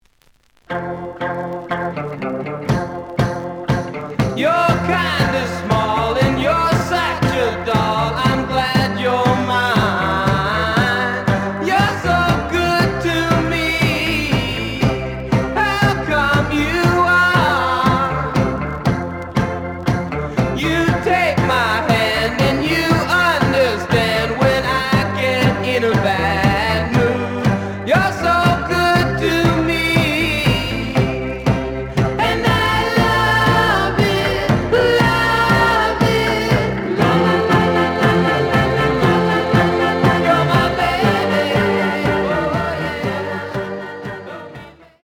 The audio sample is recorded from the actual item.
●Genre: Rock / Pop
B side plays good.